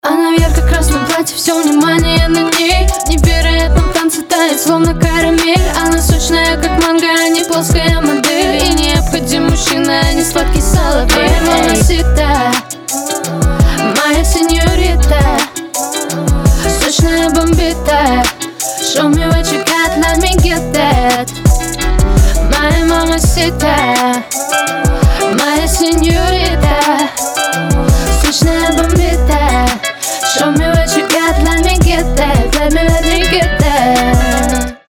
• Качество: 320, Stereo
женский вокал
dance
RnB